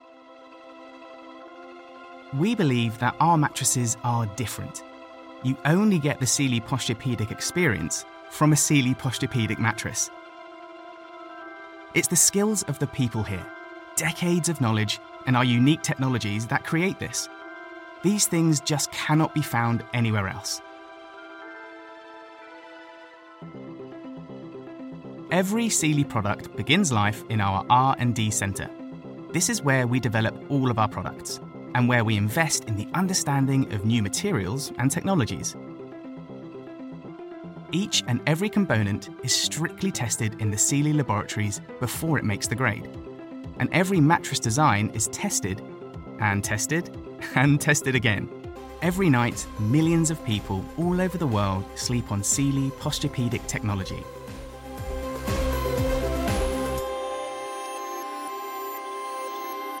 Inglés (Británico)
Comercial, Joven, Natural, Travieso, Amable
Explicador